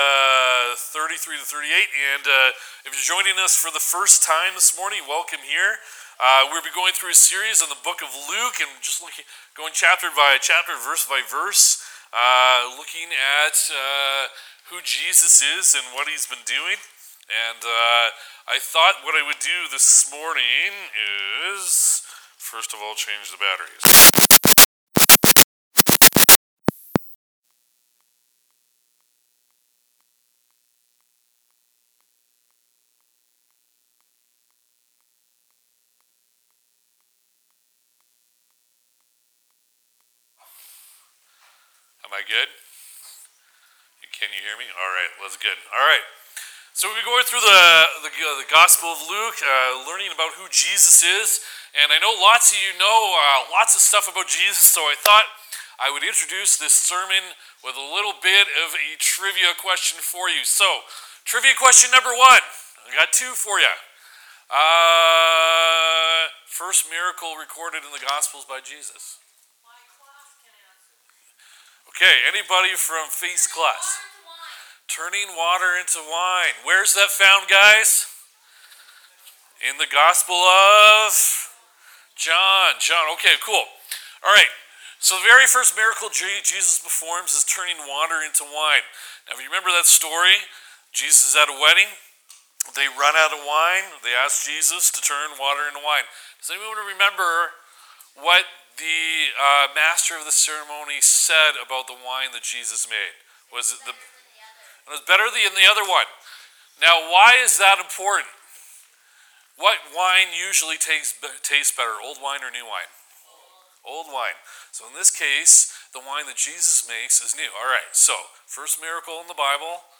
Download Download From this series Current Sermon Are You Open to Change?